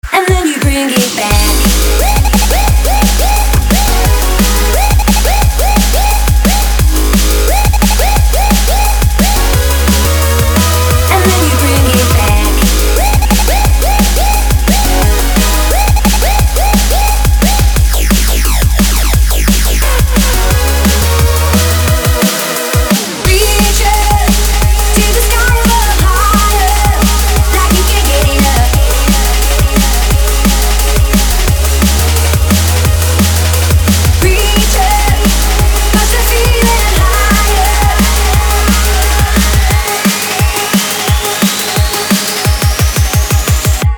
• Качество: 256, Stereo
женский вокал
dance
drum&bass